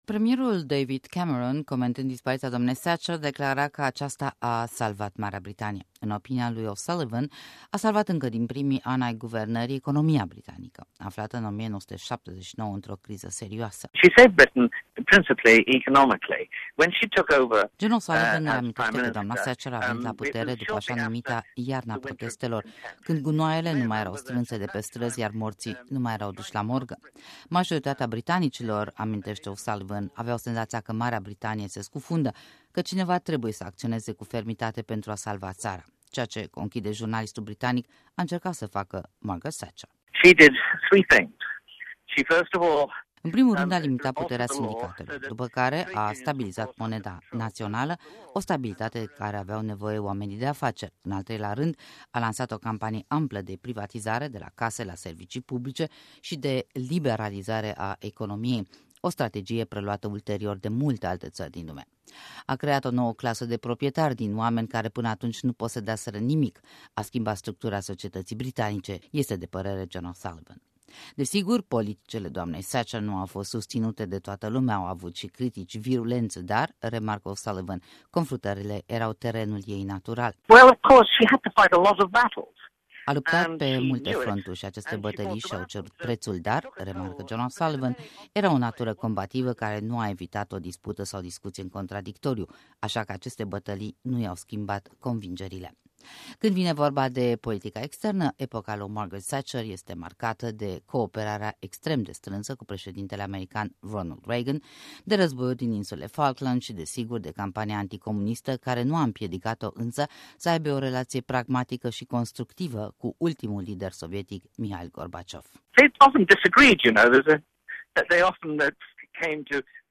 Un interviu și o evocare Margaret Thatcher